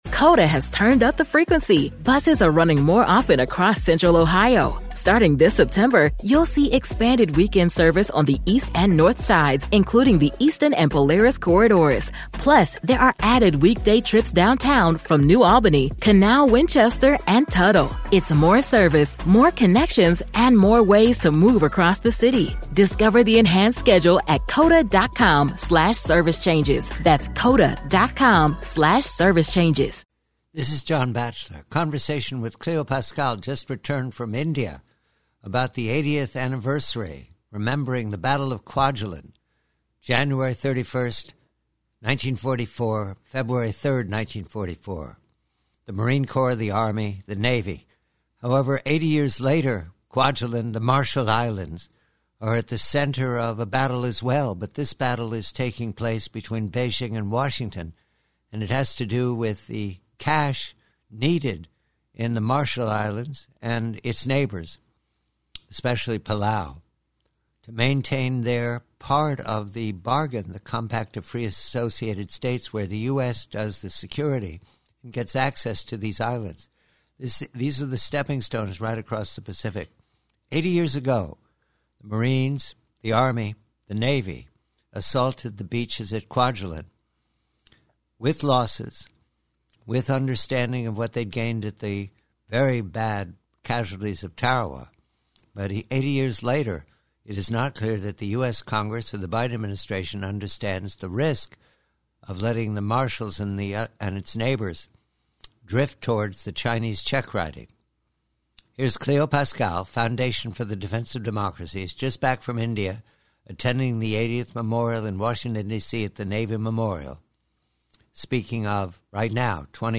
PREVIEW: Excerpt from a conversation